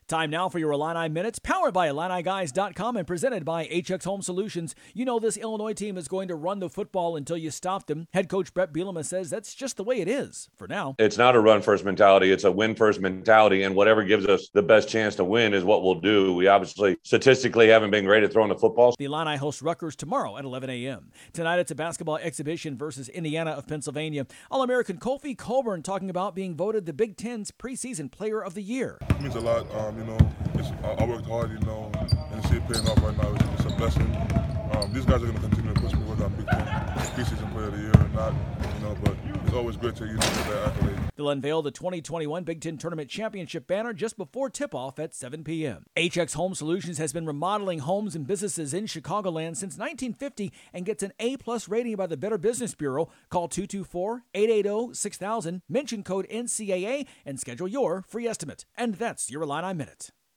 Illini head coach Bret Bielema answers questions about his "run-first" offense and Kofi Cockburn talks about being named the